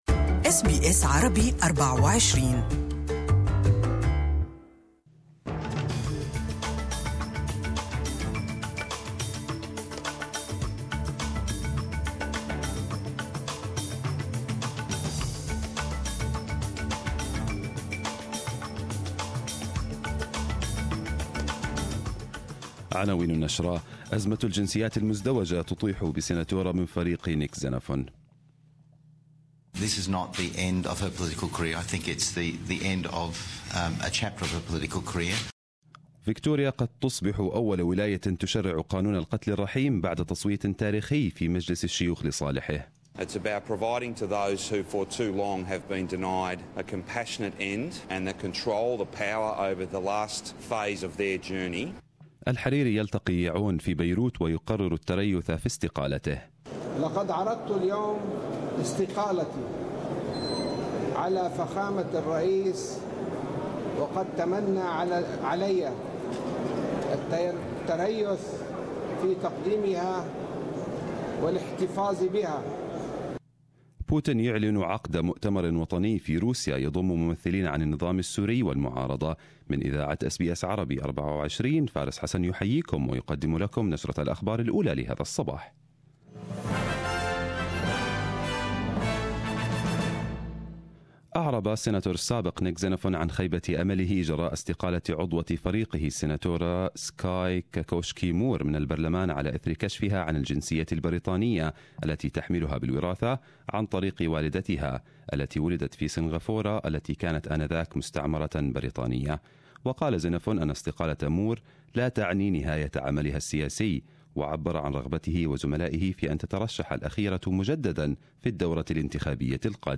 Arabic News Bulletin 23/11/2017